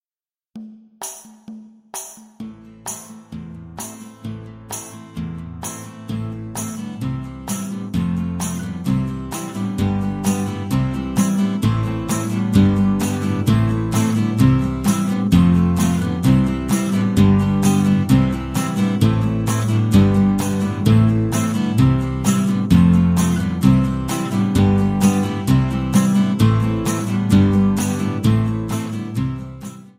Backing track Karaoke
Pop, Rock, Musical/Film/TV, 2000s